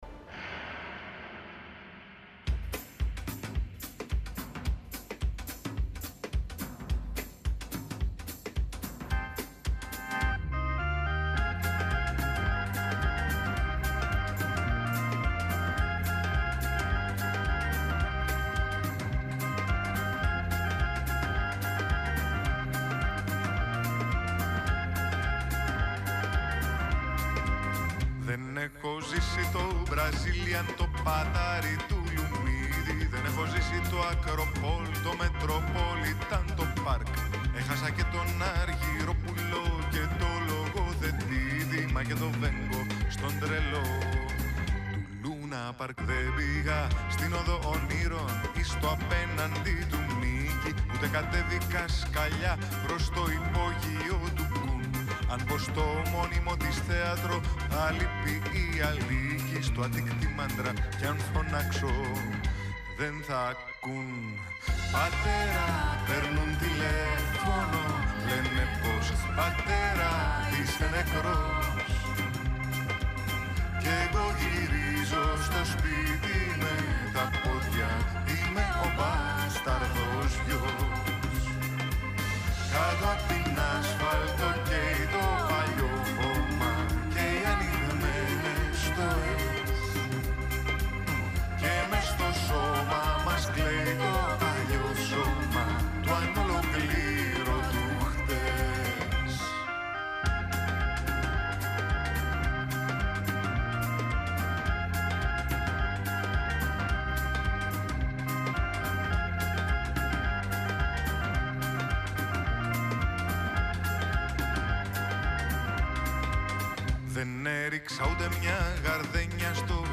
Συνέντευξη με τον Φοίβο Δεληβοριά για τη συναυλία του την Παρασκευή 6 Μαρτίου στο WE (Θεσσαλονίκη)